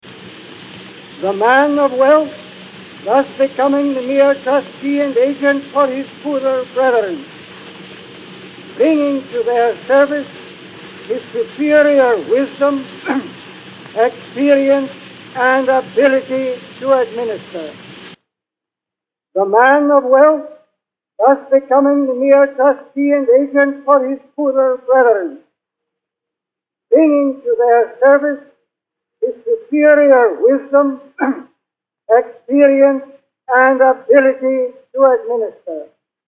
The second clip is a recording of a choir. It was recorded in a large auditorium and is riddled with clicks, pops, and an annoying low hum.
Within a couple of minutes (literally), I was able to remove most of the hum, clean up the clicks and pops, and enhance the choir voices with the push of a few buttons. What you hear on this clip is the original audio followed by the restored audio (this clip is also gently mastered with Izotope’s Ozone):